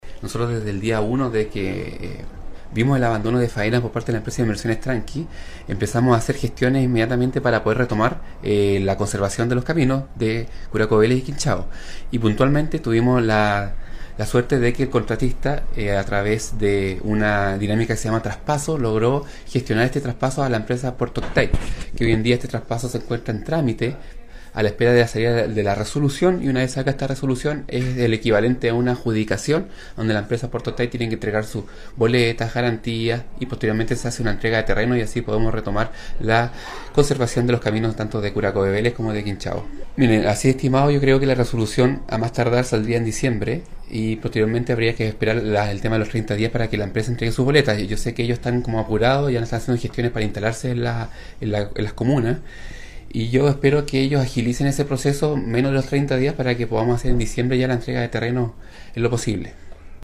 Comencemos en la comuna de Quinchao, donde estuvo el día de ayer el jefe provincial de Vialidad Gastón Werner, confirmando esta buena noticia, que se retomarán las labores allí y también en Curaco de Vélez a través de la empresa Puerto Octay, como lo notificó a los alcaldes de ambas comunas.